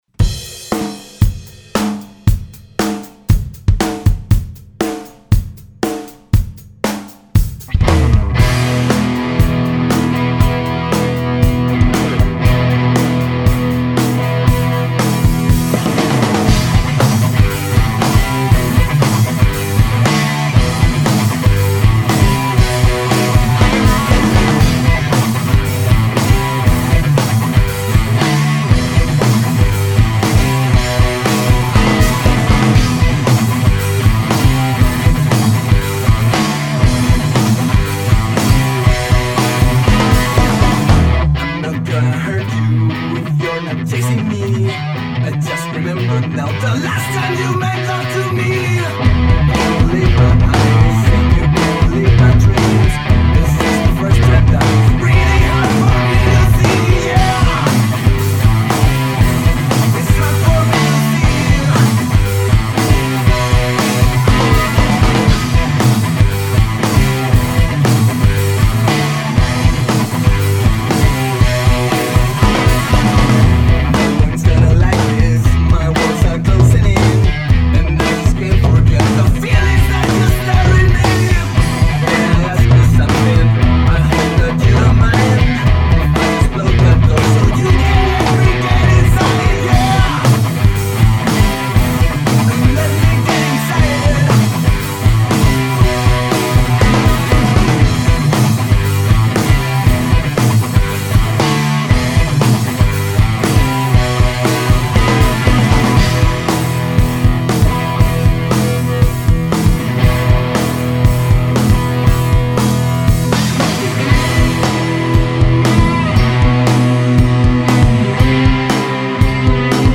Estilo musical: ROCK HIPERCALÓRICO